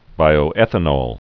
(bīō-ĕthə-nôl, -nōl, -nŏl)